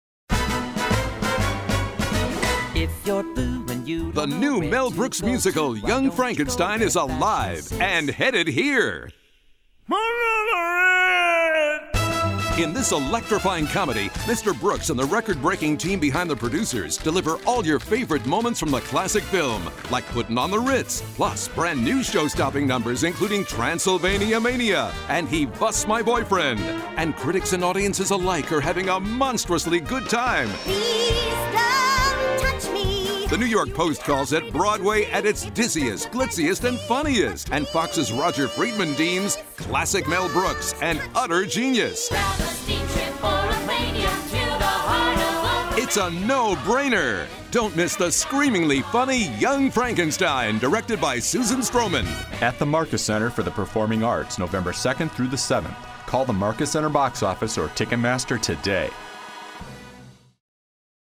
Young Frankenstein Radio Commercial